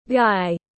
Chàng trai tiếng anh gọi là guy, phiên âm tiếng anh đọc là /ɡaɪ/.
Guy /ɡaɪ/